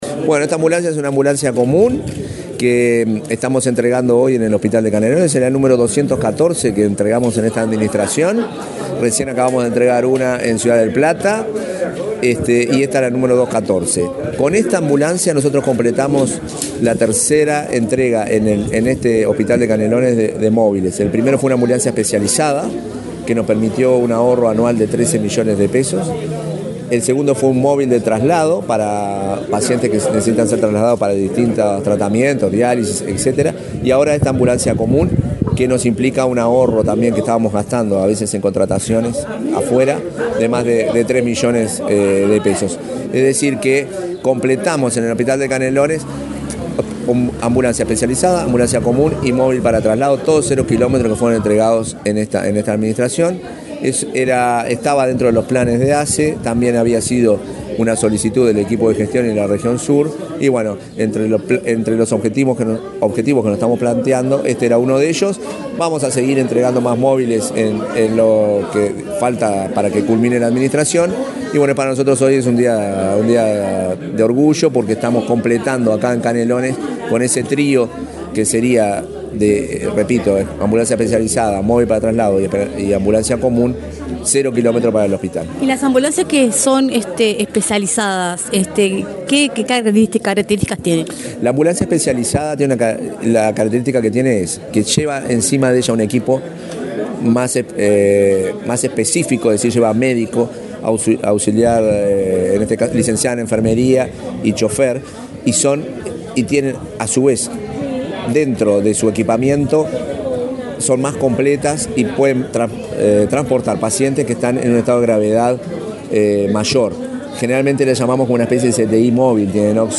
Declaraciones del presidente de ASSE, Marcelo Sosa
Declaraciones del presidente de ASSE, Marcelo Sosa 22/11/2024 Compartir Facebook X Copiar enlace WhatsApp LinkedIn El presidente de la Administración de los Servicios de Salud del Estado (ASSE), Marcelo Sosa, dialogó con Comunicación Presidencial, luego de encabezar el acto de entrega de una ambulancia en el hospital de Canelones.